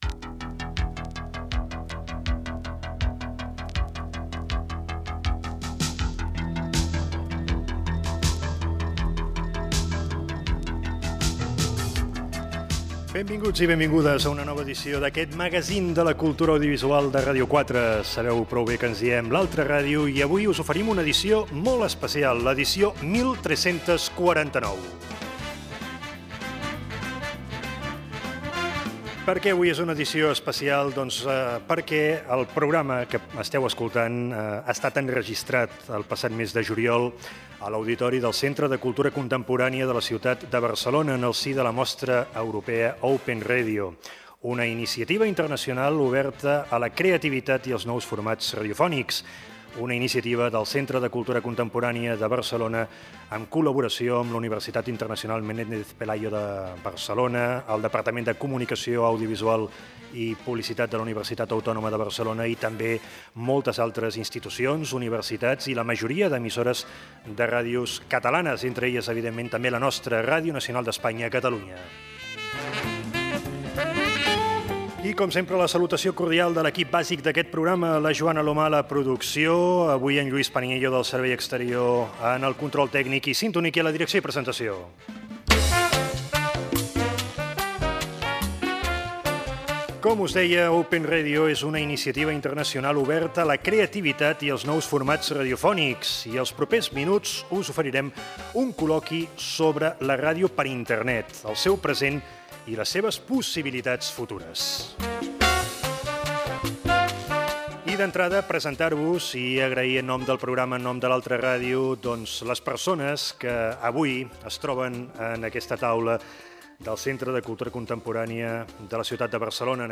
bf3d24fadefb2edfc8b0d22cb652a79d5cbe5710.mp3 Títol Ràdio 4 Emissora Ràdio 4 Cadena RNE Titularitat Pública estatal Nom programa L'altra ràdio Descripció Especial Open Radio. Sintonia, presentació i col·loqui sobre la ràdio per Internet
Espai enregistrat el 17 de juliol de 2002 al Centre de Cultura Contemporània de Barcelona CCCB) quan es feia Open Radio.